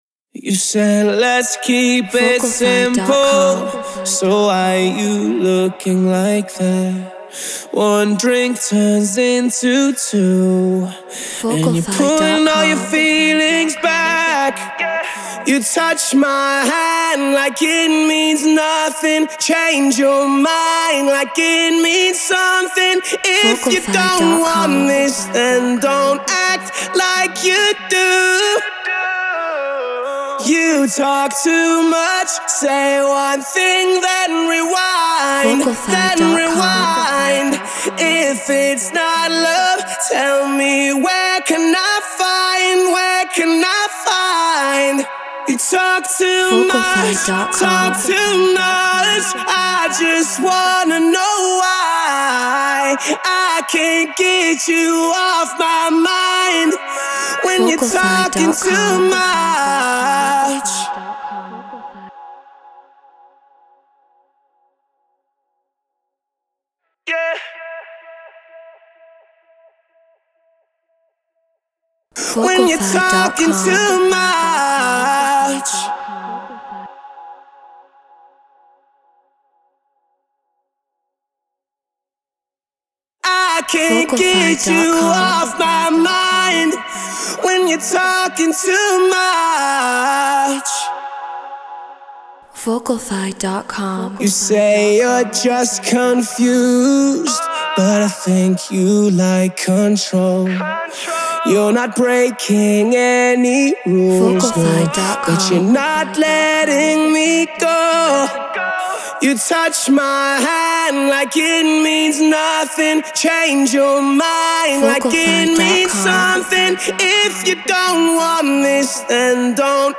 Progressive House 128 BPM C#min